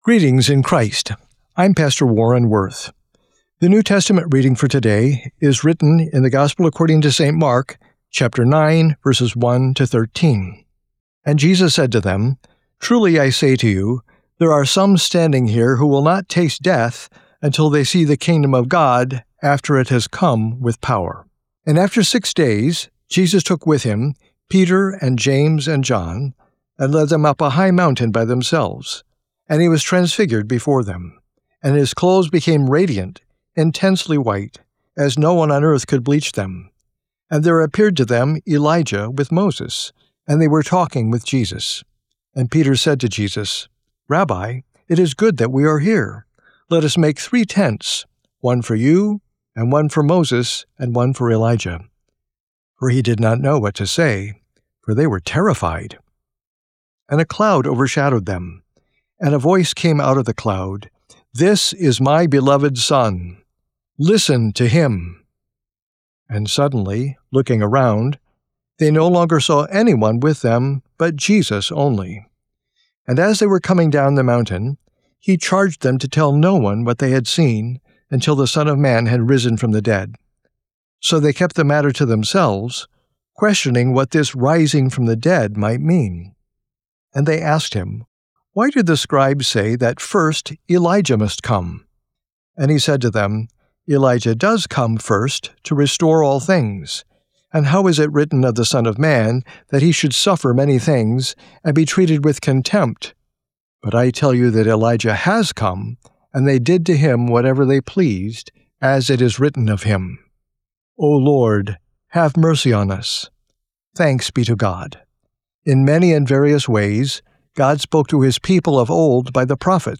Morning Prayer Sermonette: Mark 9:1-13
Hear a guest pastor give a short sermonette based on the day’s Daily Lectionary New Testament text during Morning and Evening Prayer.